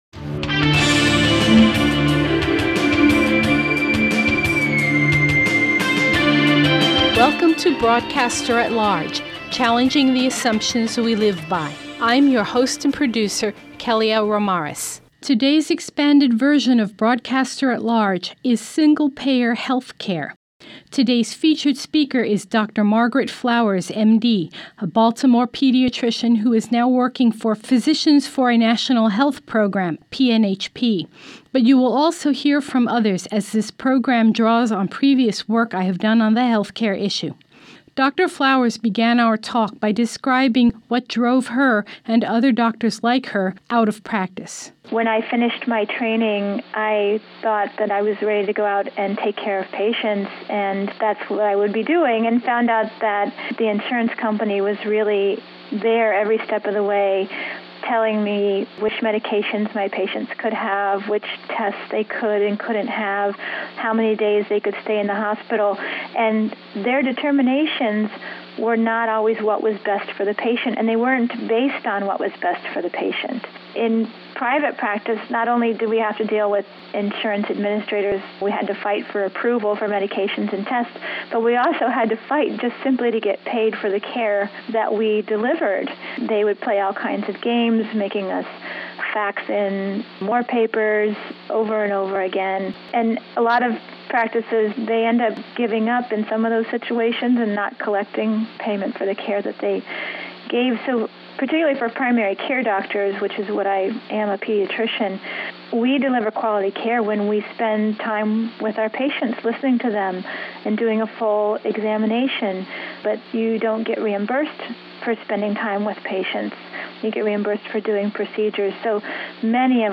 59:00 Documentary on why we need single payer